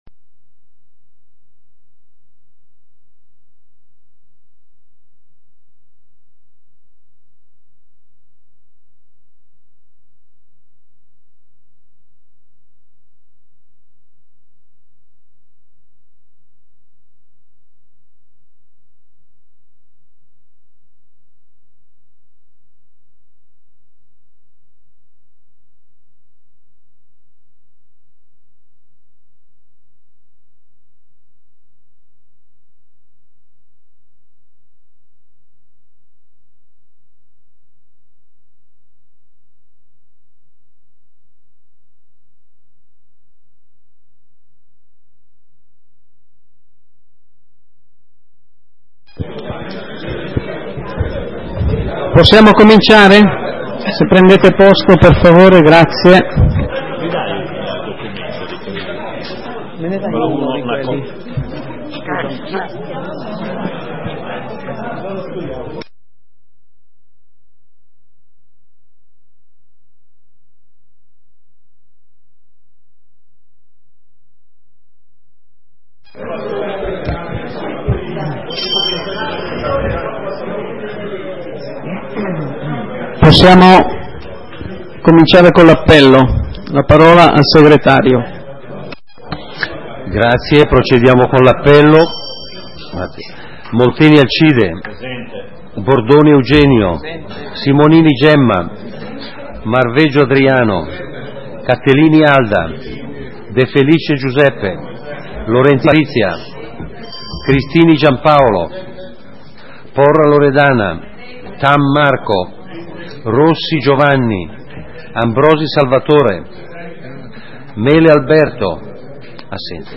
Seduta consiglio comunale del 30 settembre 2011 - Comune di Sondrio